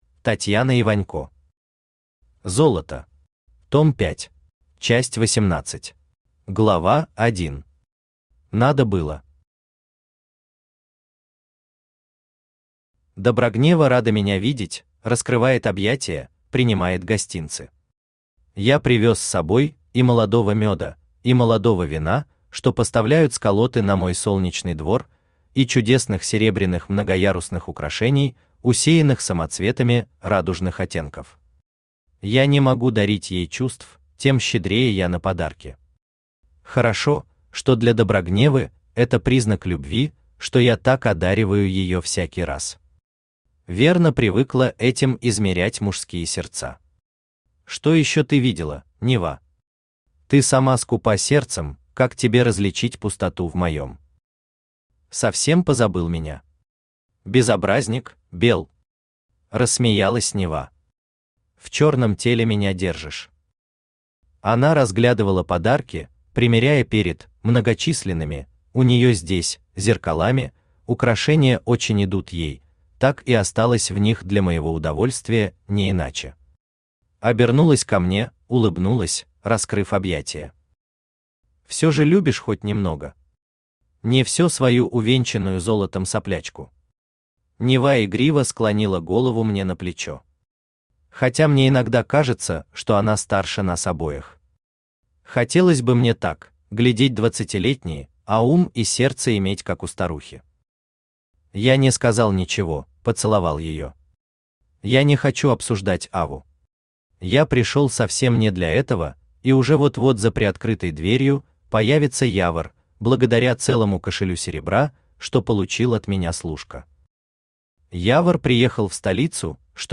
Аудиокнига Золото. Том 5 | Библиотека аудиокниг
Том 5 Автор Татьяна Вячеславовна Иванько Читает аудиокнигу Авточтец ЛитРес.